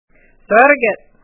Words and Phrases will have separate files so that you can listen to the correct pronunciation of the words.